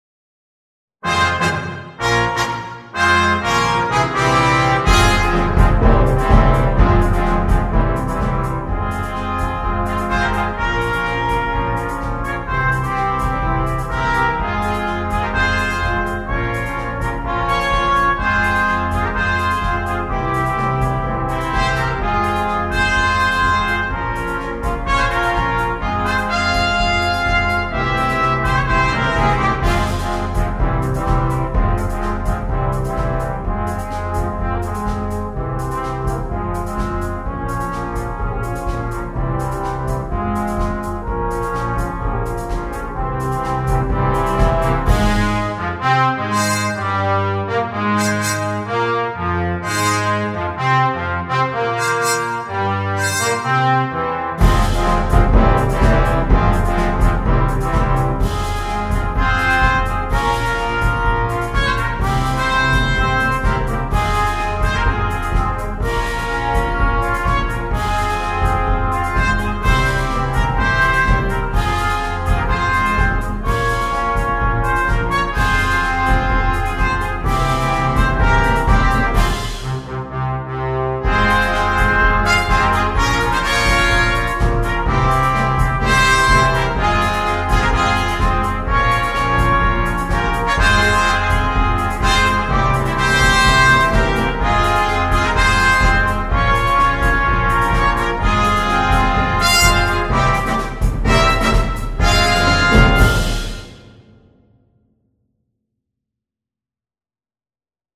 2. Orchestre Juniors (flex)
8 parties et percussions
sans instrument solo
Musique légère
Partie 1 en ut (8va): Flûte
Partie 1 en sib: 1ère Clarinette, 1ère Trompette/Cornet
Partie 8 en ut: Tuba, Contrebasse, Basson
Percussion